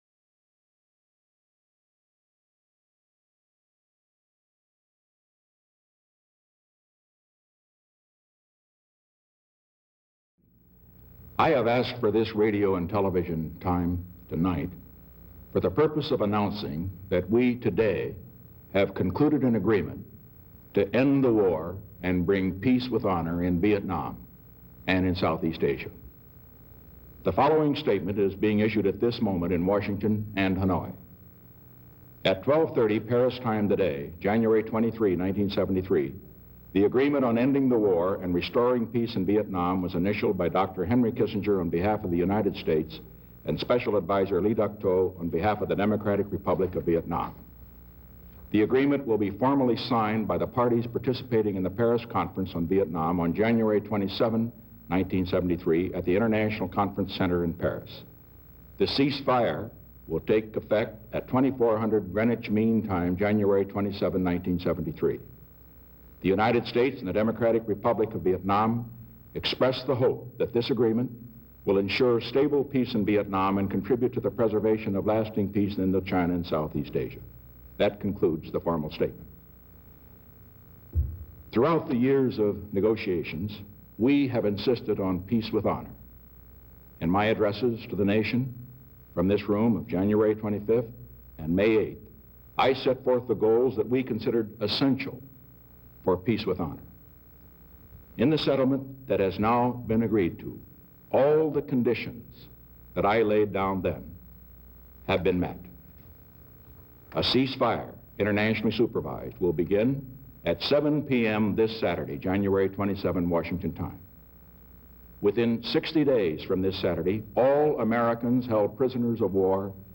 January 23, 1973: Address to the Nation Announcing an Agreement on Ending the War in Vietnam